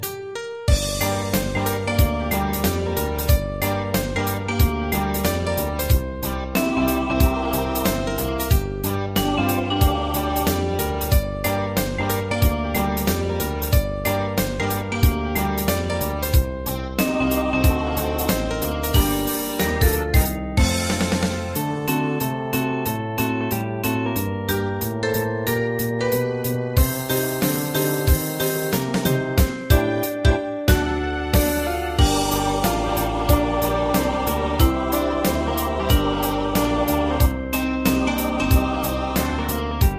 カテゴリー: アンサンブル（合奏） .
日本のポピュラー